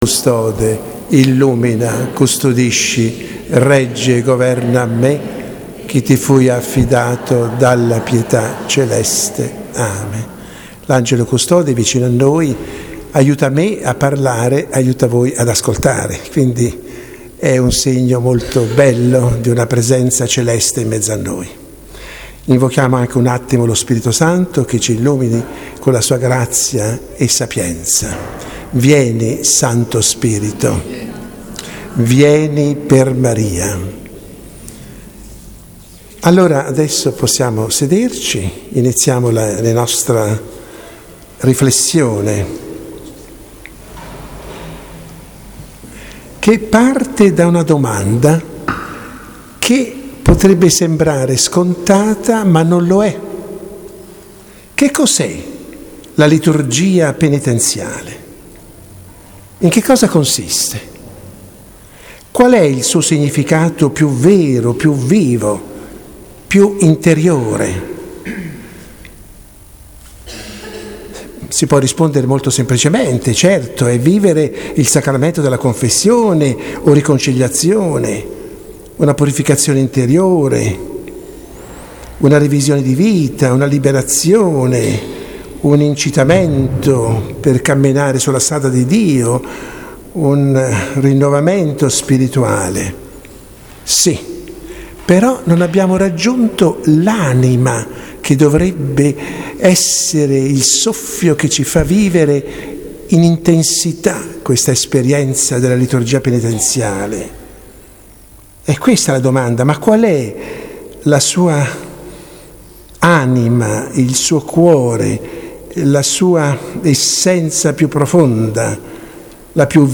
predicazione